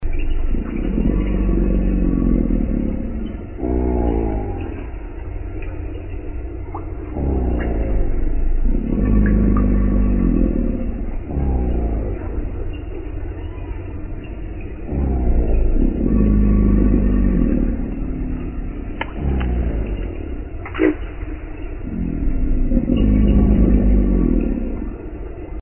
جلوه های صوتی
دانلود صدای گوریل برای کودکان از ساعد نیوز با لینک مستقیم و کیفیت بالا
برچسب: دانلود آهنگ های افکت صوتی انسان و موجودات زنده